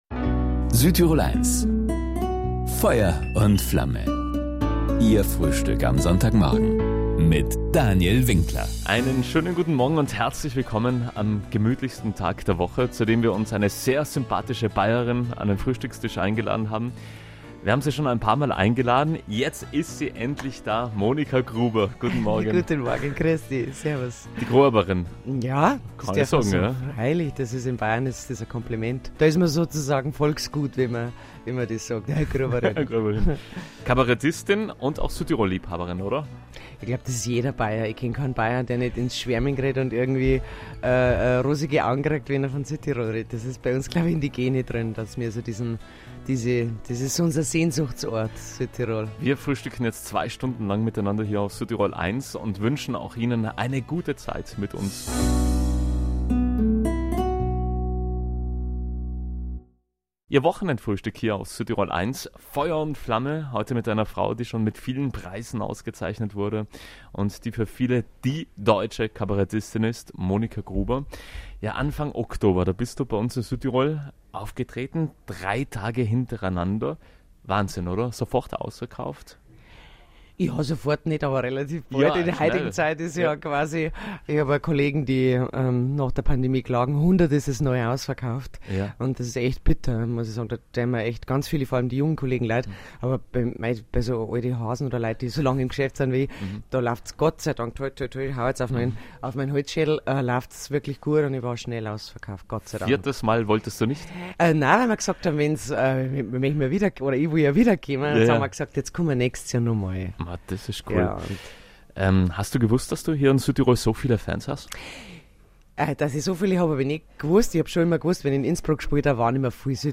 Ein Gespräch über Heimat, guten Humor und ganz viel Südtirol, denn unser Land ist und bleibt Monika Grubers Sehnsuchtsort!